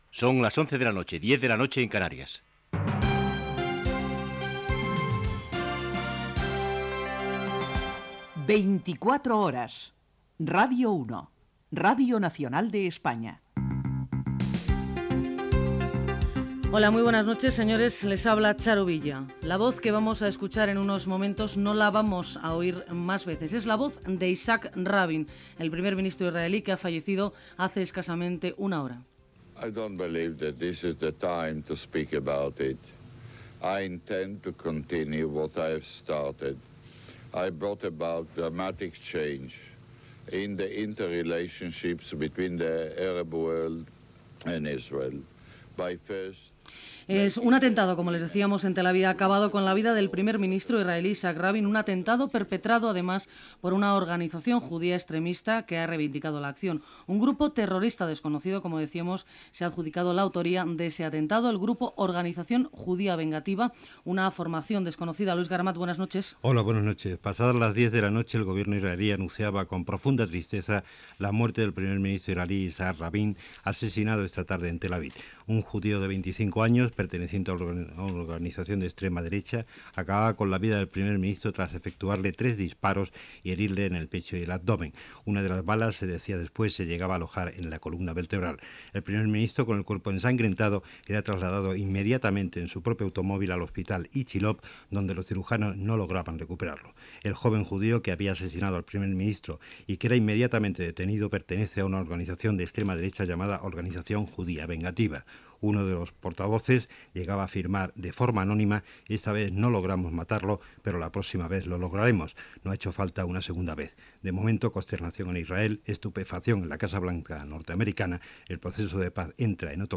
Hora, careta del programa, informació de l'assassinat del primer ministre israelià Yitshaq Rabbín
Informatiu